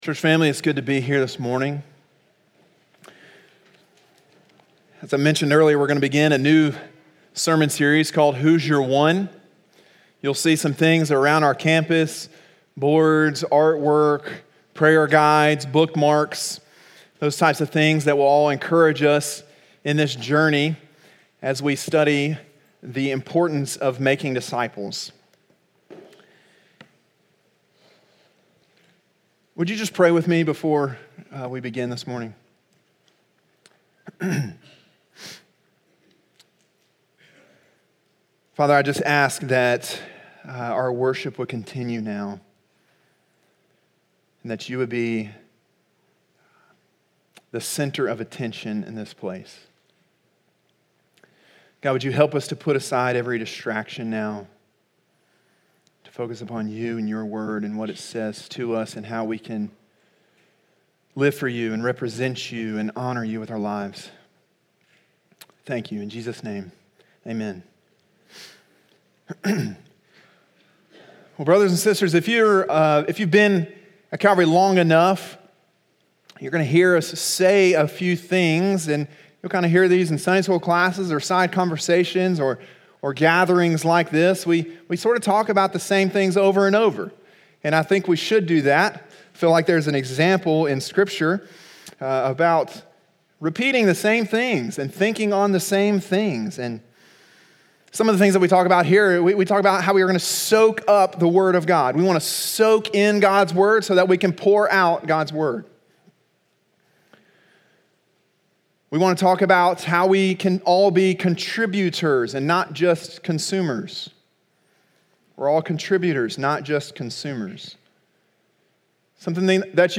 Sermon: “What Is a Disciple?”